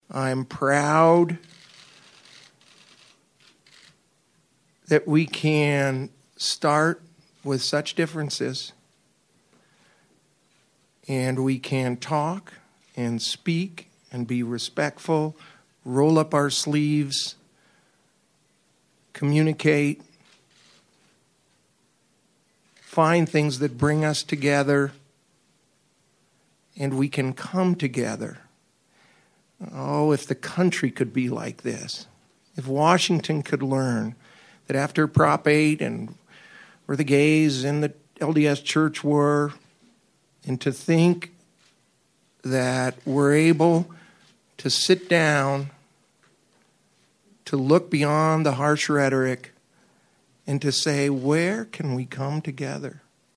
Advocates say they've been working for years to include language in Utah law protecting people on basis of sexual orientation and gender identity. Utah Senator Jim Debakis (D) spoke Wednesday at a joint news conference introducing the bill.